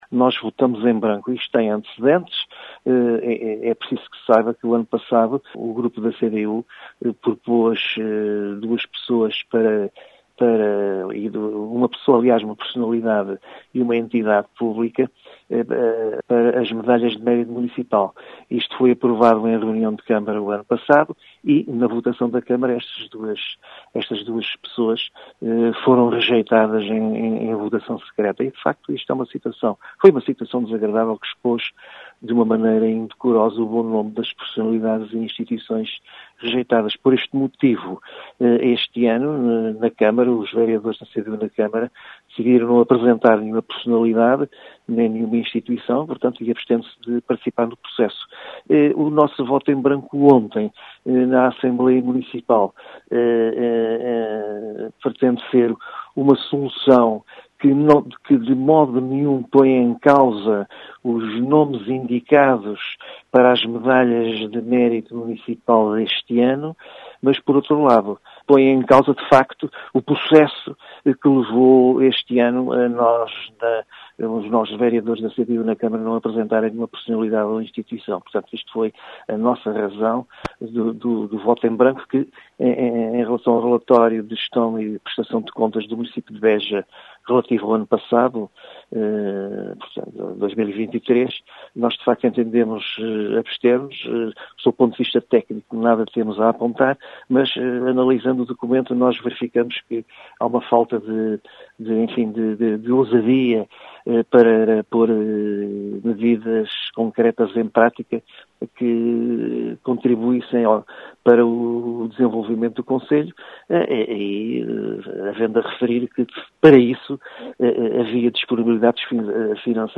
As explicações foram deixadas por Bernardo Loff, eleito da CDU na Assembleia Municipal de Beja, que explica o sentido de voto da bancada municipal da CDU.